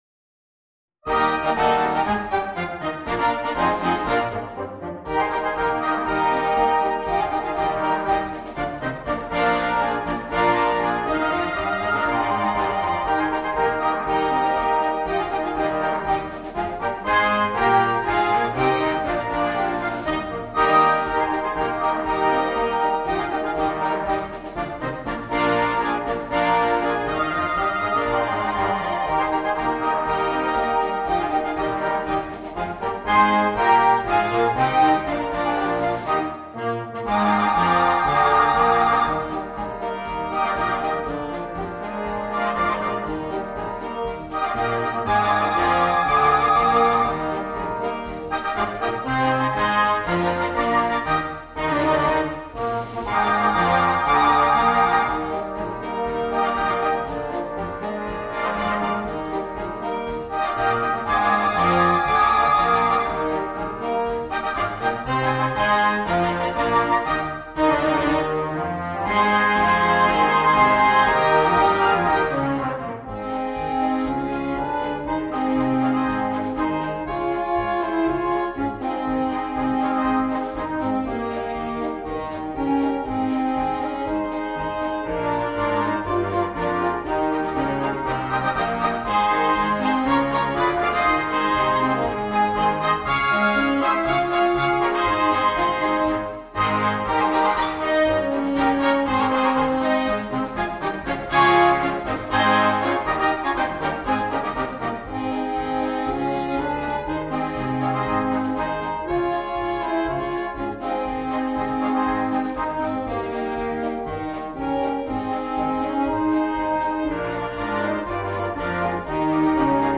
Per banda
MARCE DA SFILATA